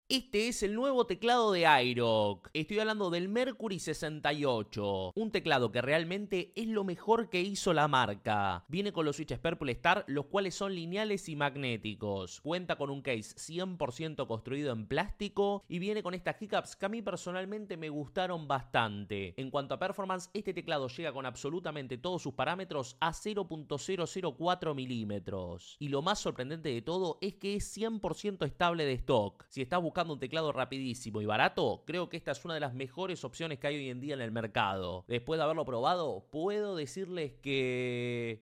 El mejor teclado calidad sound effects free download